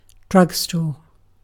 Ääntäminen
IPA : /ˈdrʌɡ.stɔ(ɹ)/